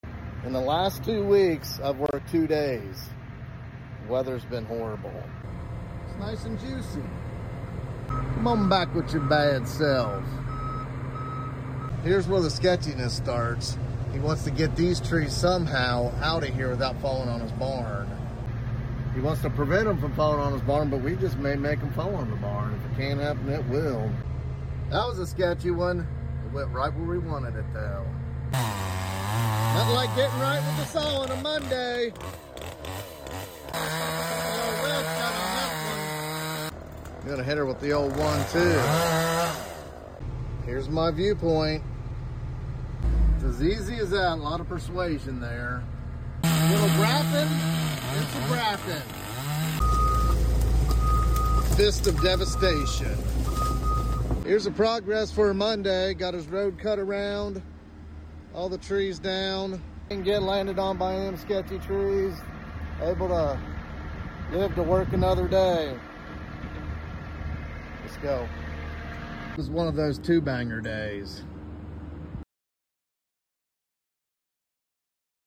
Using Mp3 Sound Effect Tree falls on barn? Using the Excavator do tree work.